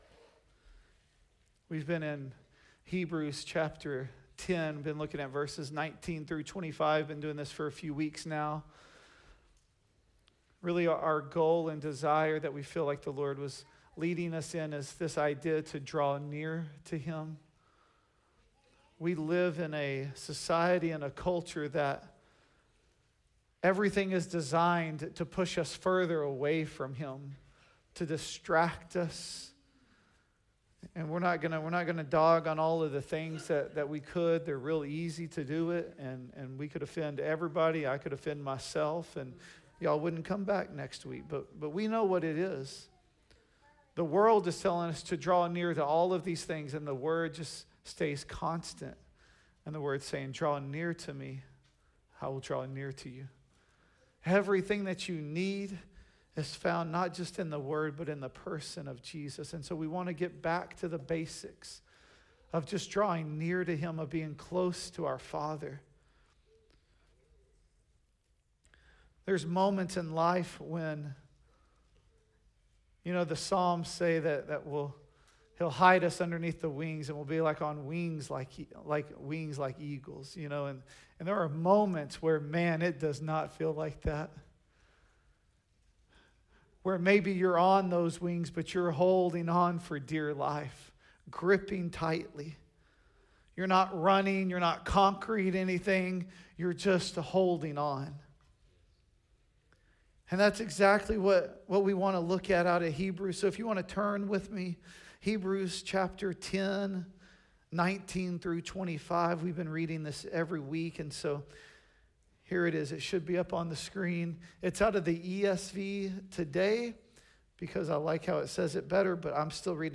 The Gathering at Adell Audio Sermons Let Us Hold Fast Play Episode Pause Episode Mute/Unmute Episode Rewind 10 Seconds 1x Fast Forward 30 seconds 00:00 / 00:45:36 Subscribe Share RSS Feed Share Link Embed